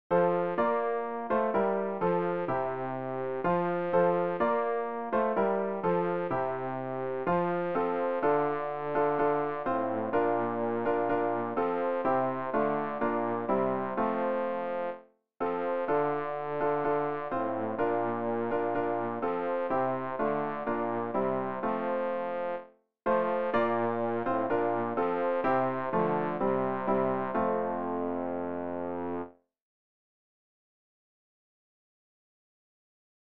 rg-472-christus-ist-auferstanden-freud-ist-in-allen-landen-bass.mp3